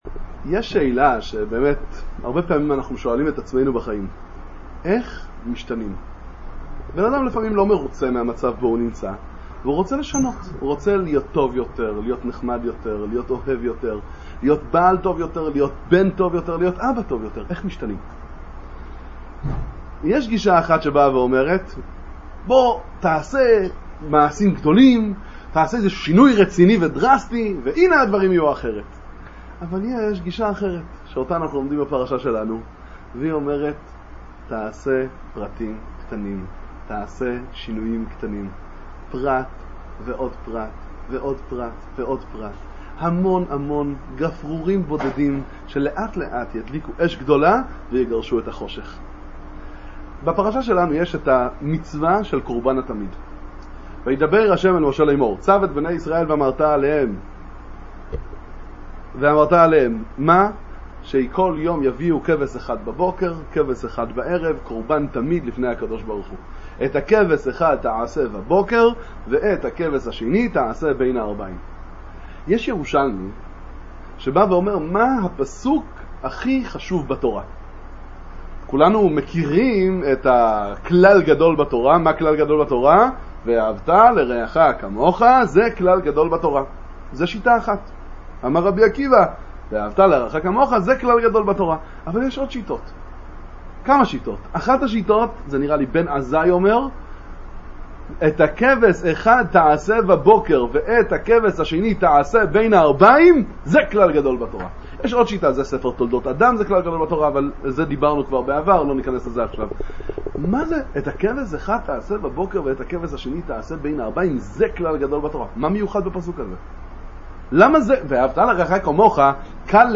דבר תורה קצר לפרשת פינחס